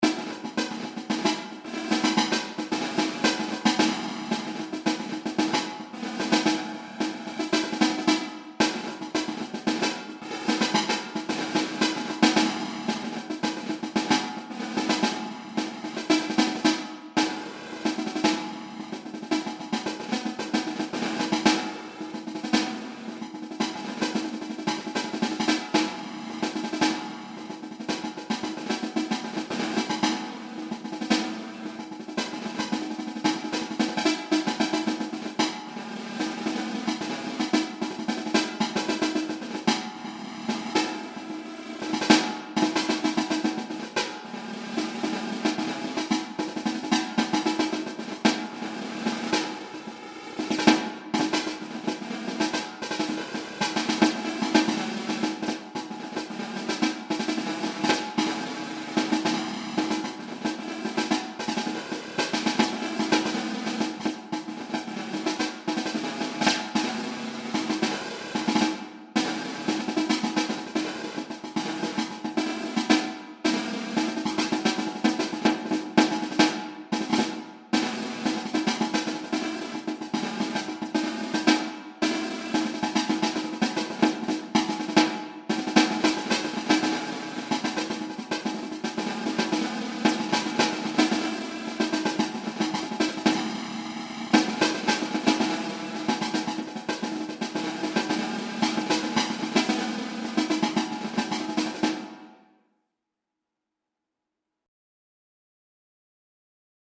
Ongestemd Marcherend Slagwerk
Snare Drum Bass drum